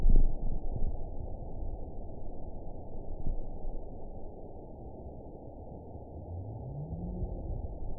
event 917061 date 03/17/23 time 16:14:19 GMT (2 years, 1 month ago) score 7.94 location TSS-AB05 detected by nrw target species NRW annotations +NRW Spectrogram: Frequency (kHz) vs. Time (s) audio not available .wav